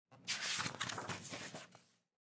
Book Page (2).wav